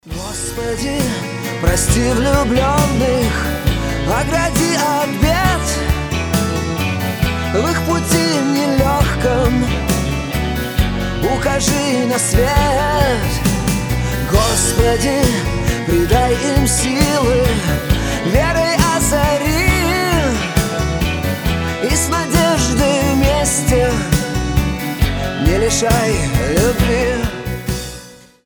• Качество: 320, Stereo
мужской голос
душевные
спокойные